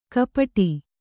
♪ kapaṭi